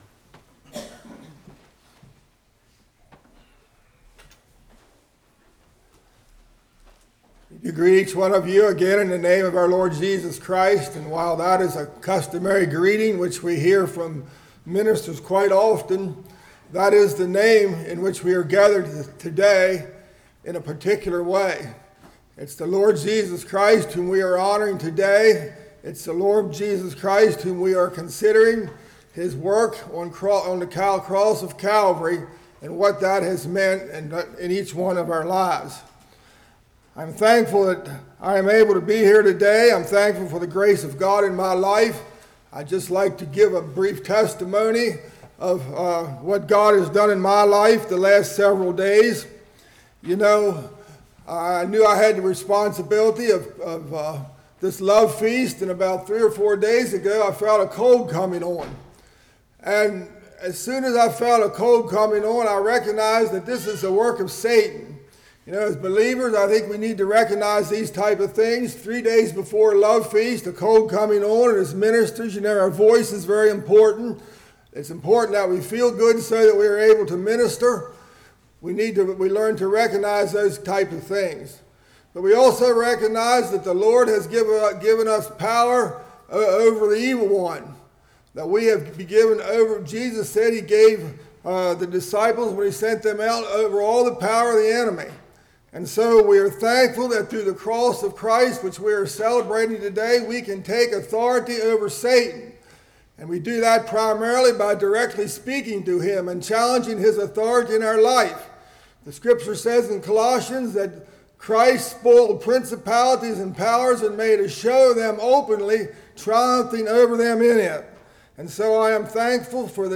Passage: 1 Corinthians 11:1-33 Service Type: Morning